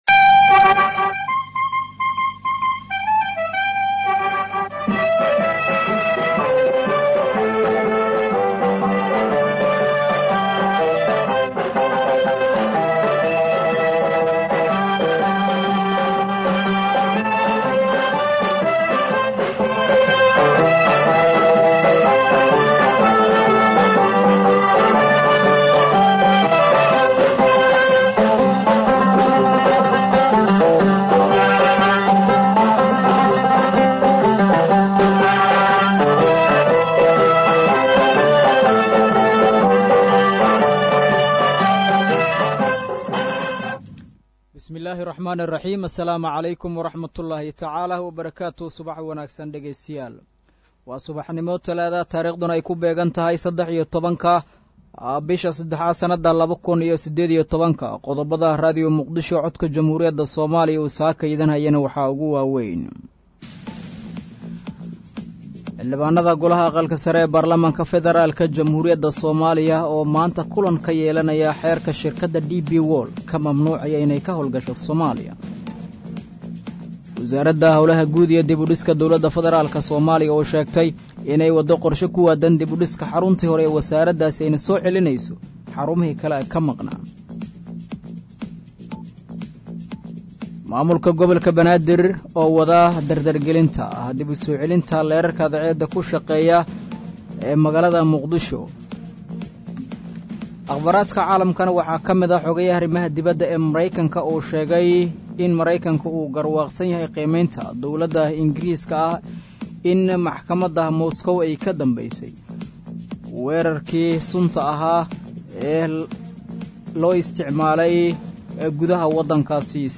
Dhageyso: Warka Subax ee Radio Muqdisho